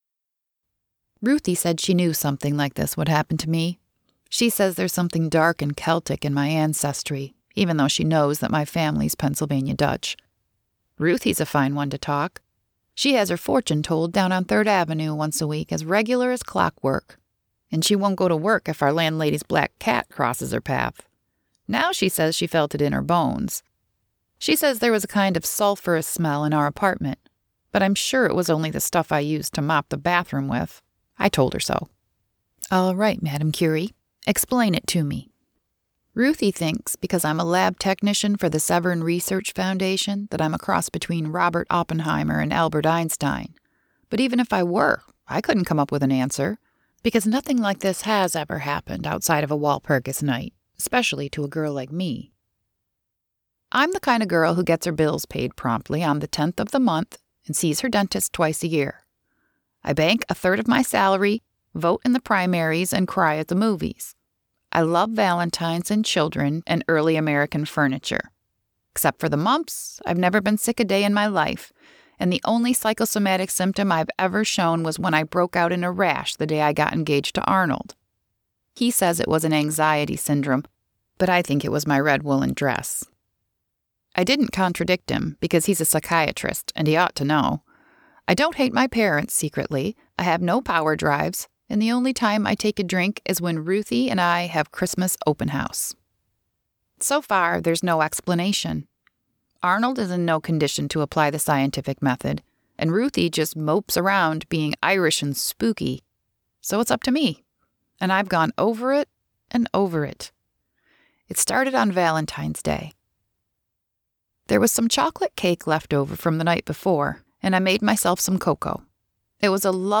THIS IS AN AUDIOBOOK